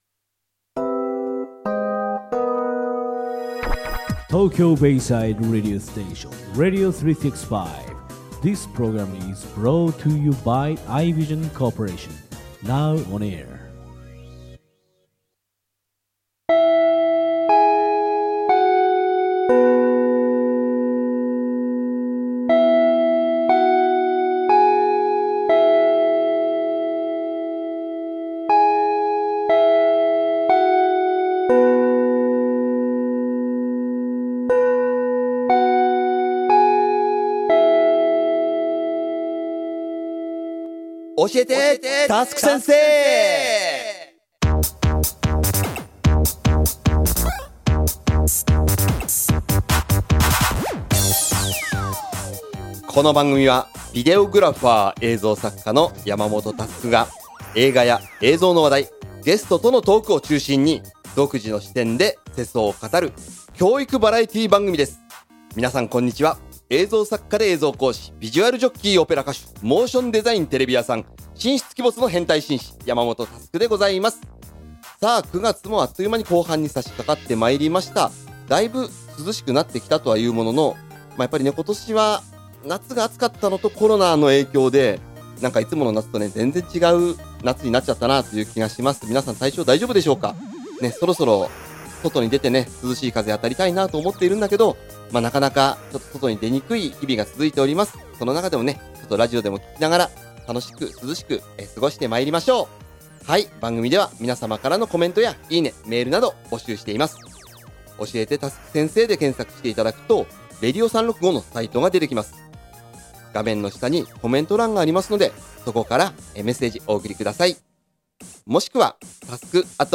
コーナー1：クリエイターズトーク